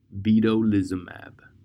Pronounce:
drug-audio-en-Vedolizumab.mp3